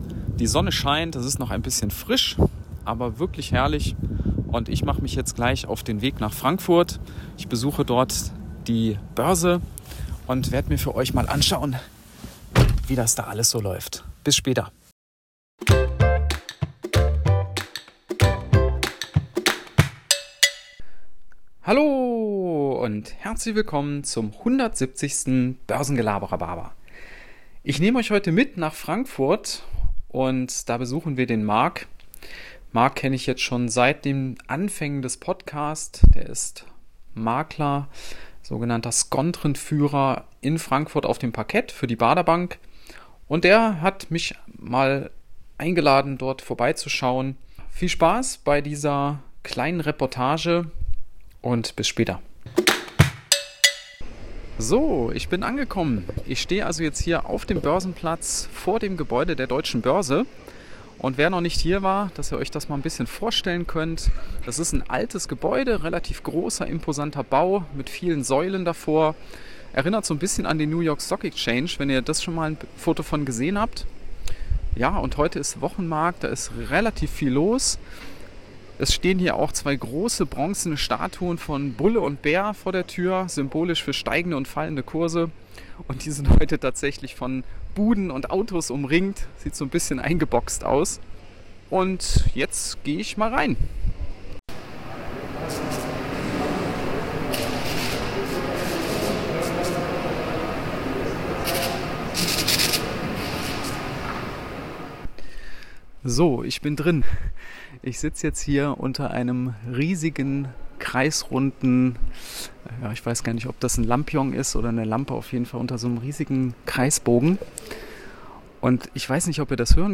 Heute gibt es eine Reportage zu meinem kürzlichen Besuch auf dem Frankfurter Parkett.
Gelaber_170_FRA_Reportage.m4a